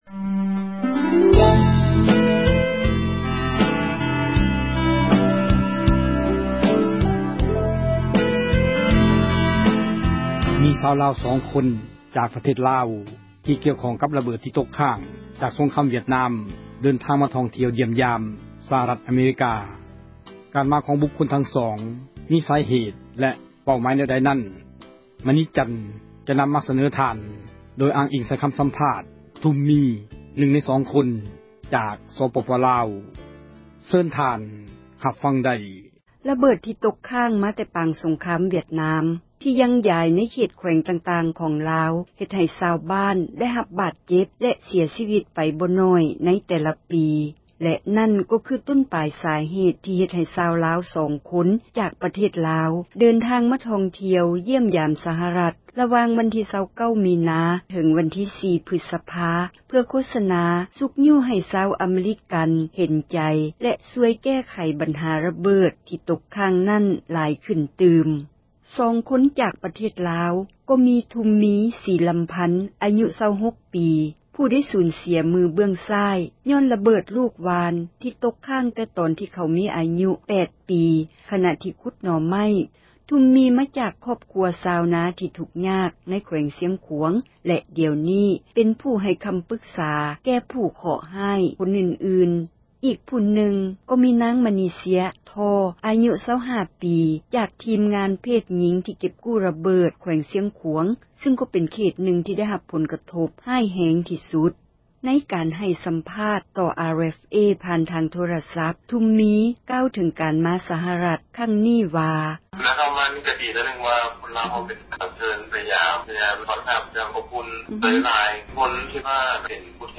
ຄໍາສັມພາດ